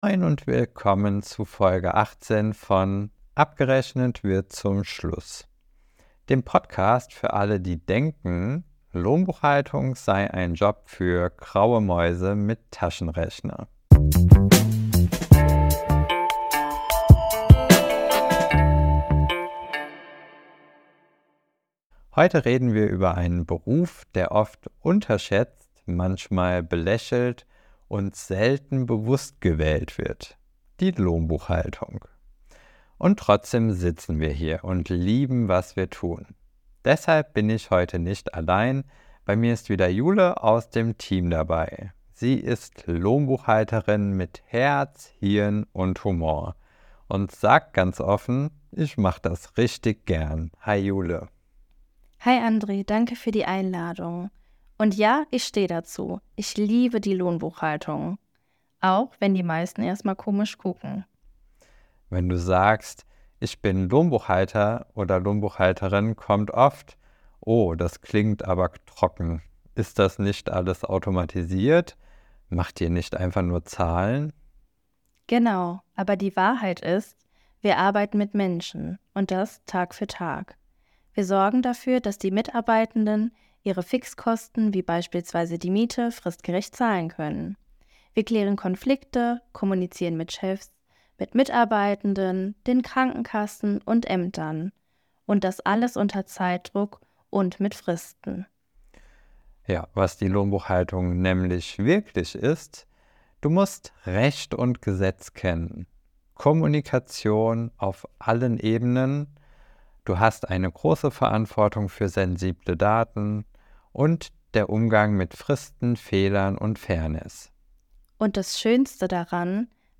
Ein inspirierendes Gespräch für alle, die in der